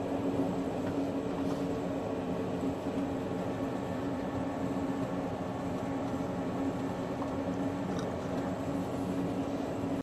Kling-Audio-Eval / Vehicle /Motor vehicle (road) /audio /10341.wav